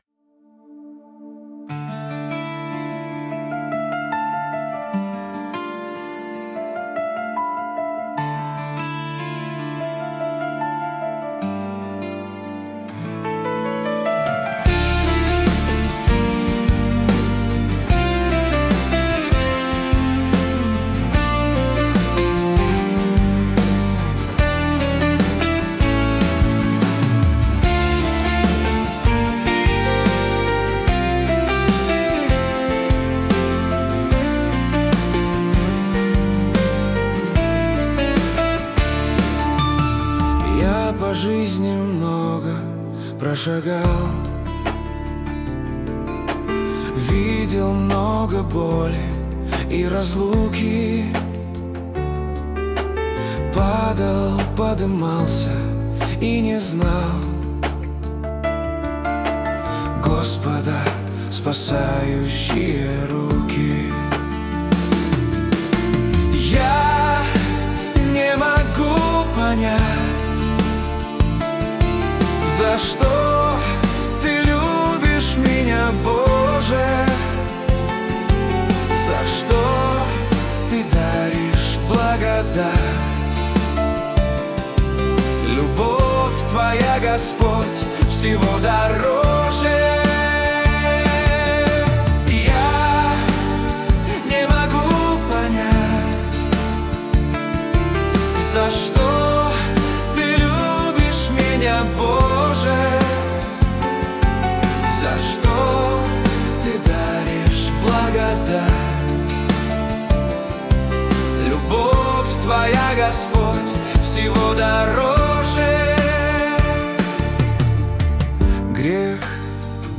песня ai
447 просмотров 112 прослушиваний 18 скачиваний BPM: 73